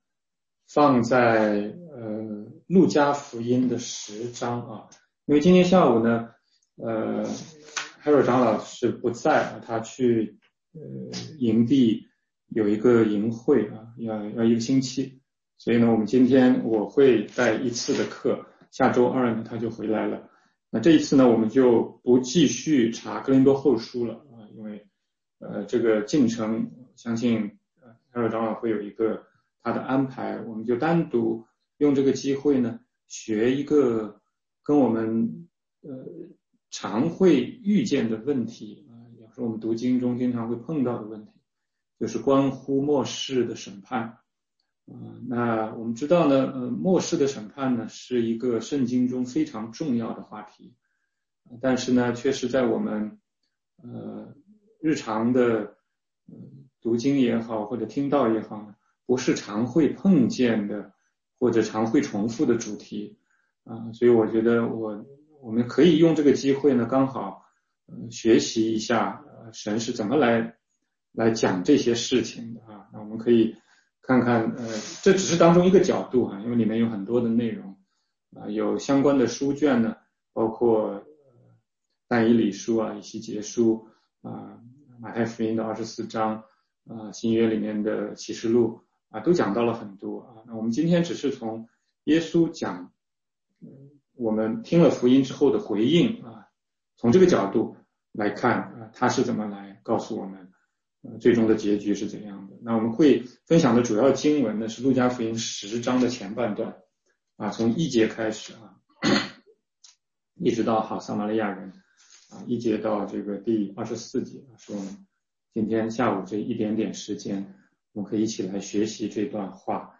16街讲道录音 - 路加福音10章1-24节：信福音不是可有可无